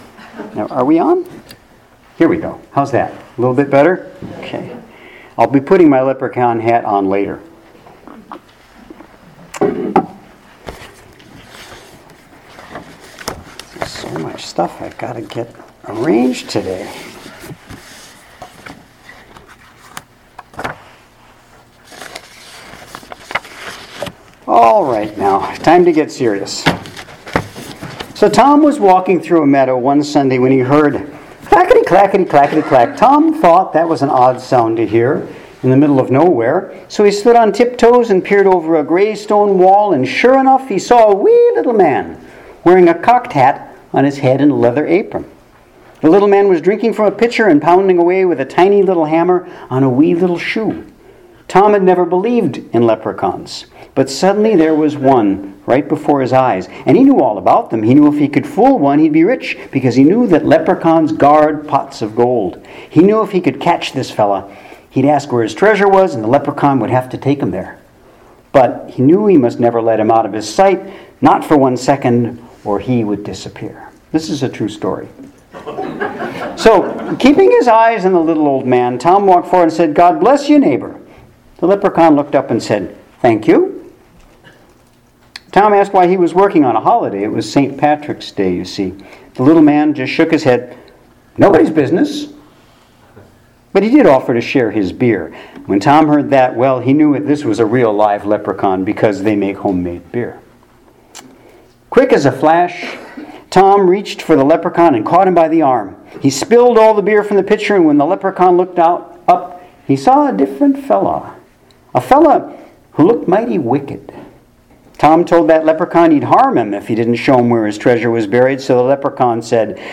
Sermon Archive | Wy'east Unitarian Universalist Congregation